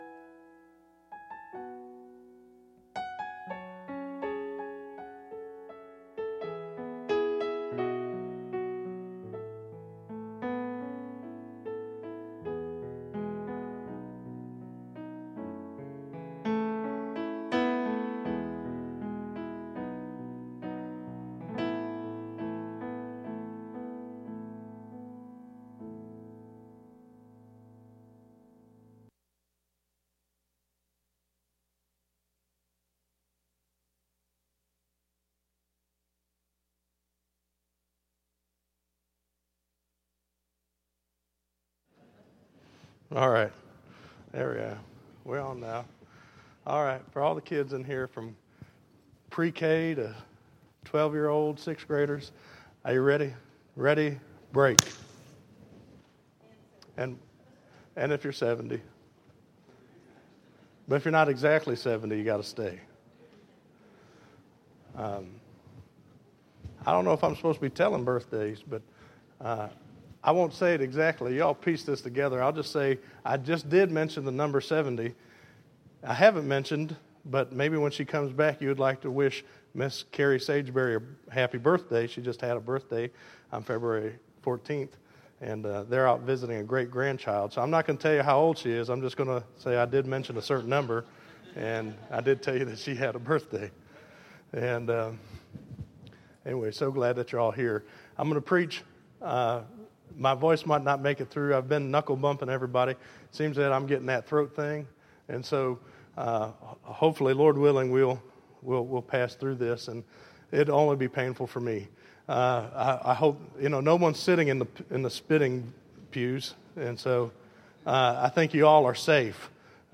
Listen to I Am Saved - 02_16_14_Sermon.mp3